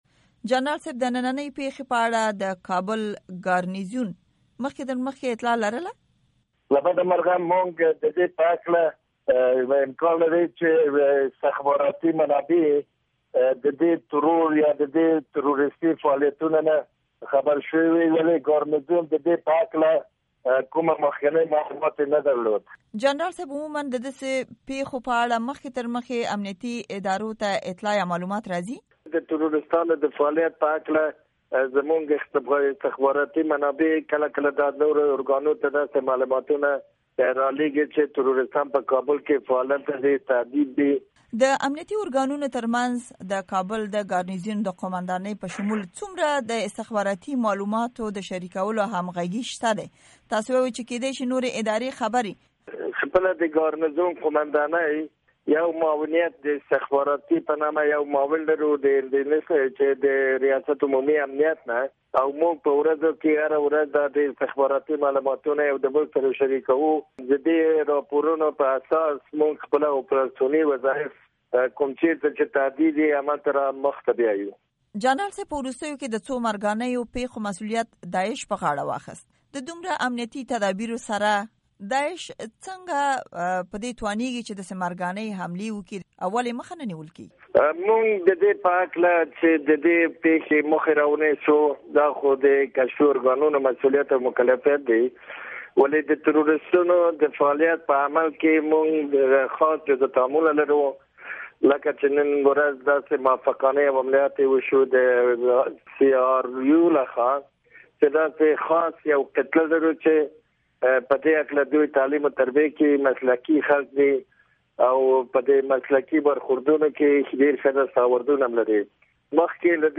امريکا غږ سره د جنرال افضل امان مرکه